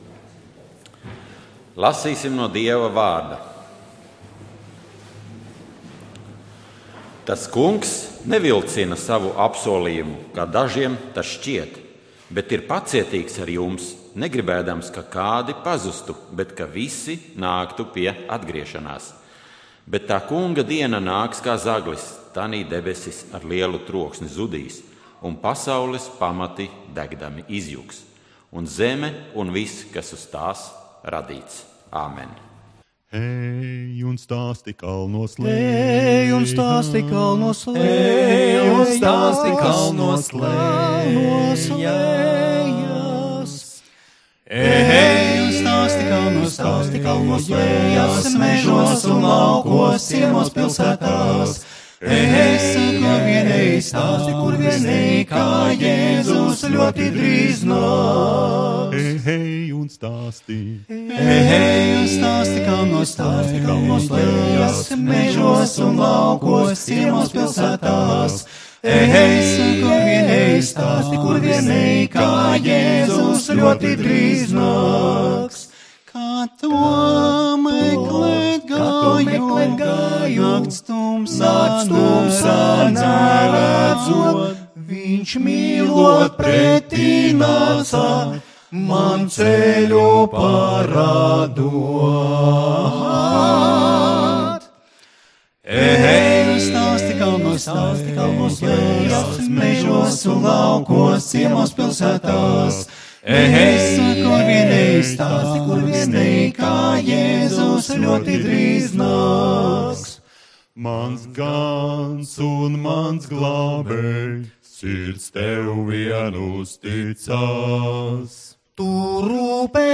Dievkalpojums 06.01.2018: Klausīties
Svētrunas